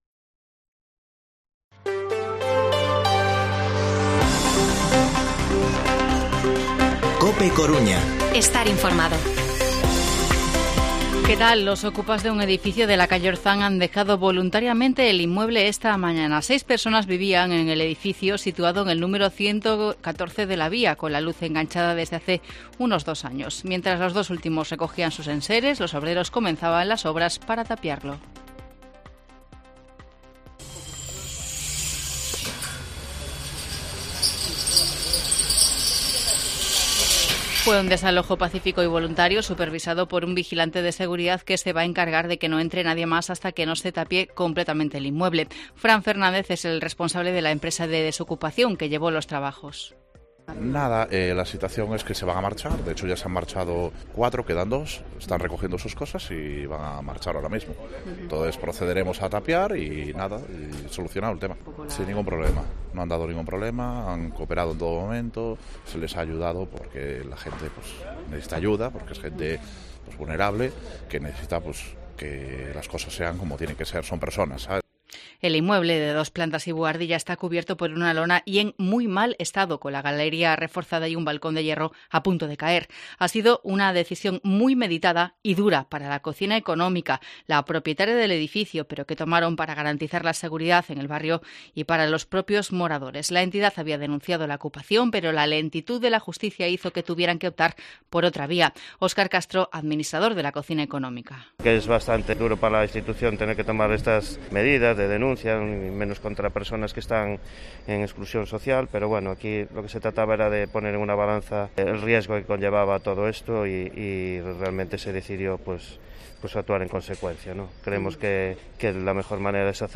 Informativo Mediodía Coruña viernes, 9 de septiembre de 2022 14:20-14:30